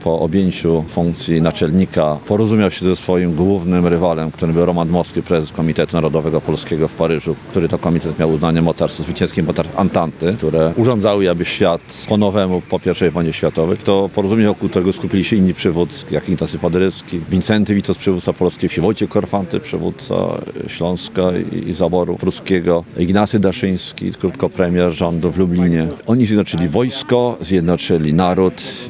Mieszkańcy Lublina uczcili 158. urodziny marszałka Józefa Piłsudskiego. Obchody odbyły się przy pomniku marszałka Józefa Piłsudskiego na Placu Litewskim.